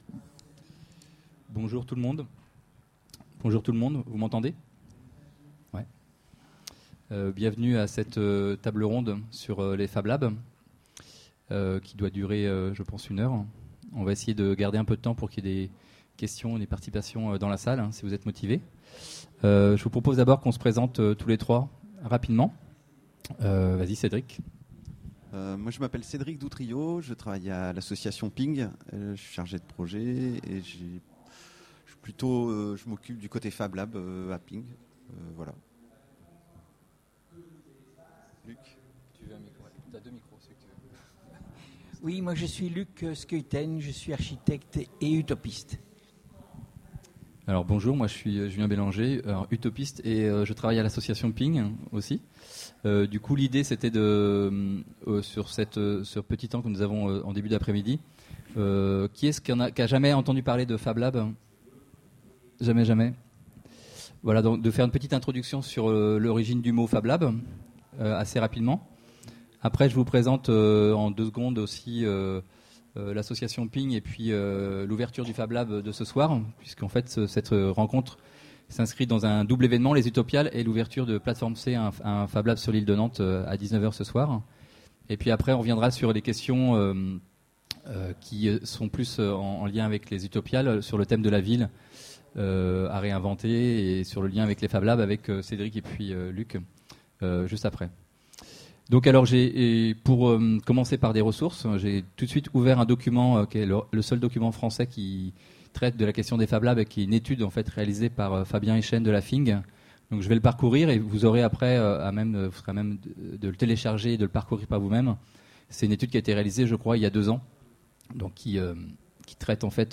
Utopiales 13 : Conférence Panorama des initiatives d'ateliers de fabrication numérique autour de nous : les fablabs